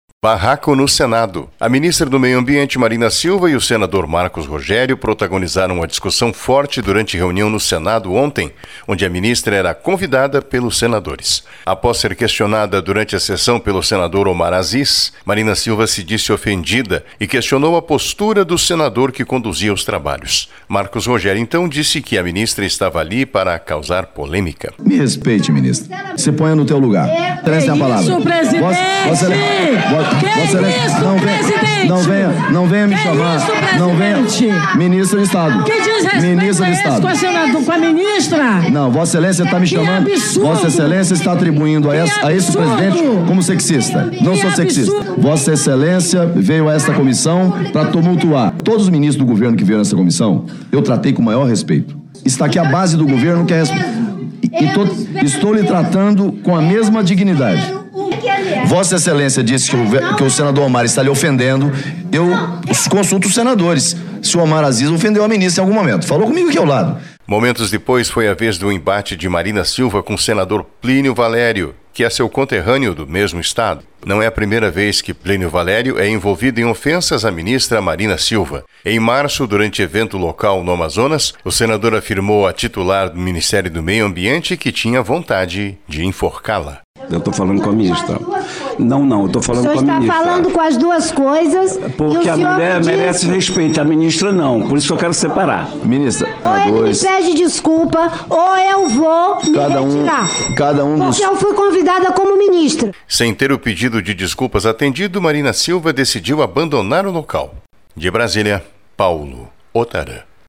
Ministra Marina Silva e Senadores protagonizam bate-boca com troca farpas durante audiência